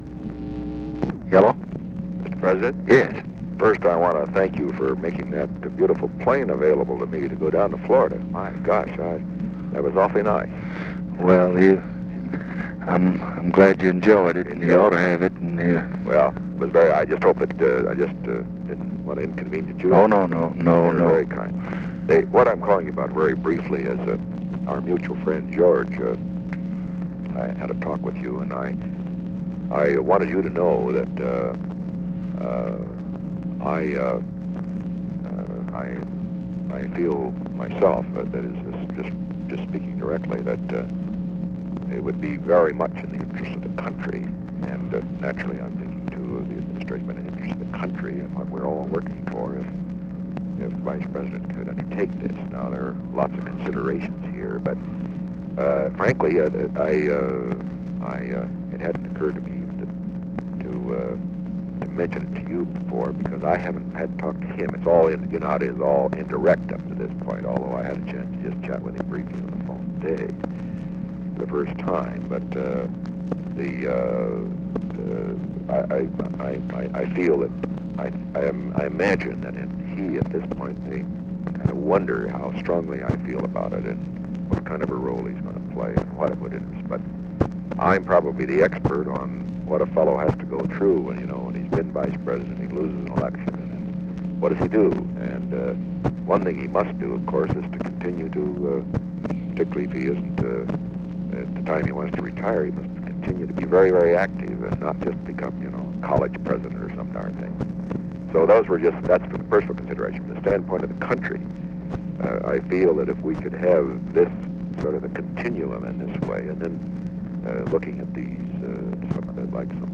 Conversation with RICHARD NIXON and OFFICE CONVERSATION, November 22, 1968
Secret White House Tapes